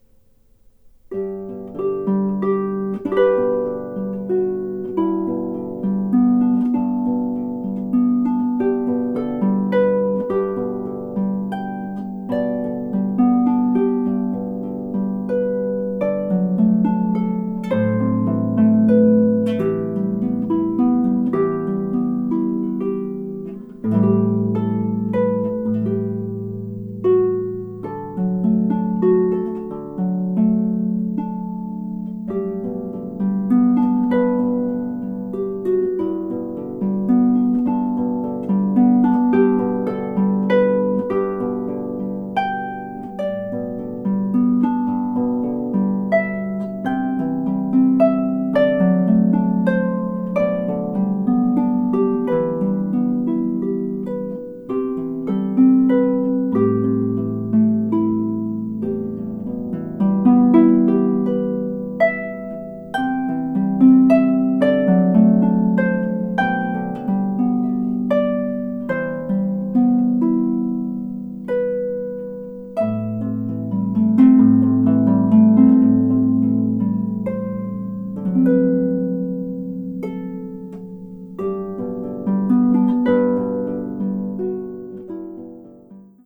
O Mio Babbino Caro – Harpist